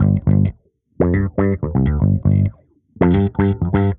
Index of /musicradar/dusty-funk-samples/Bass/120bpm